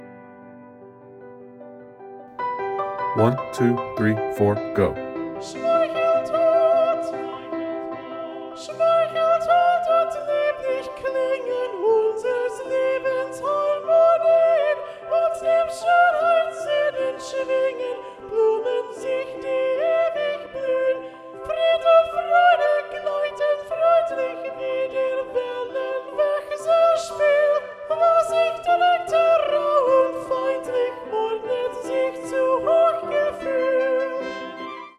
Soprano Vocal Model Recording (all Sopranos will audition on Soprano 1, not Soprano 2)